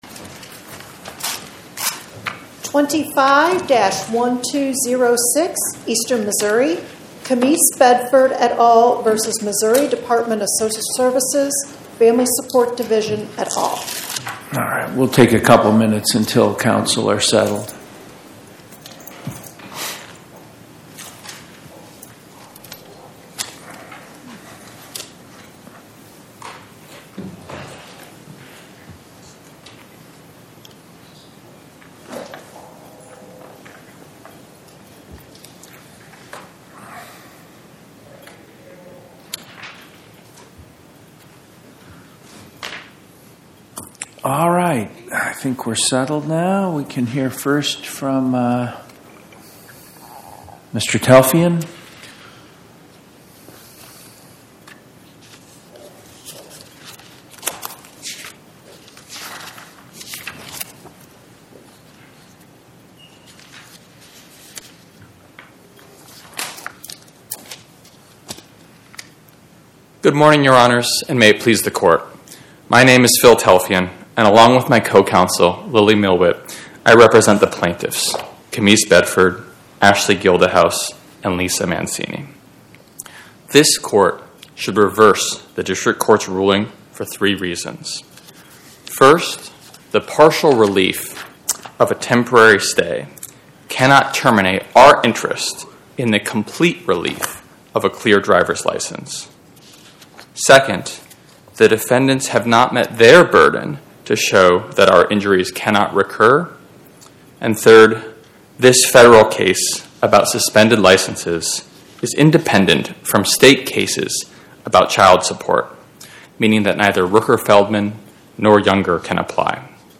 Podcast: Oral Arguments from the Eighth Circuit U.S. Court of Appeals Published On: Thu Nov 20 2025 Description: Oral argument argued before the Eighth Circuit U.S. Court of Appeals on or about 11/20/2025